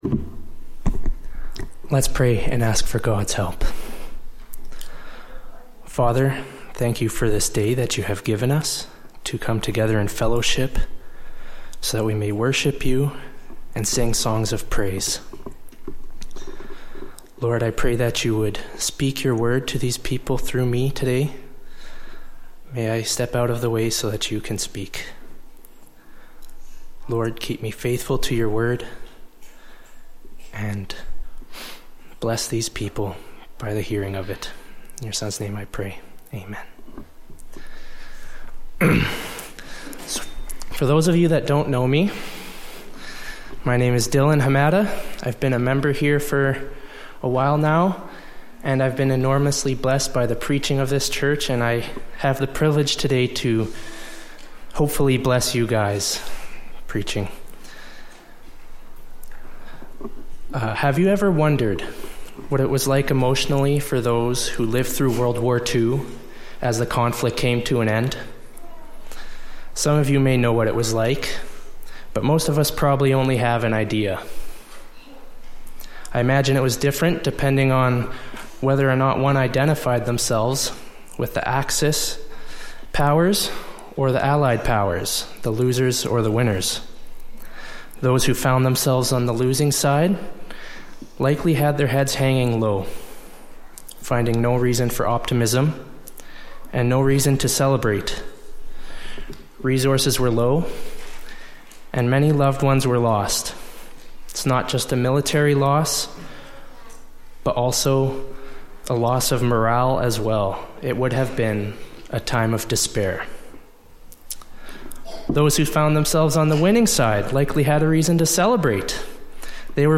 Service Type: Latest Sermon